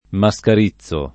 mascarizzo [ ma S kar &ZZ o ]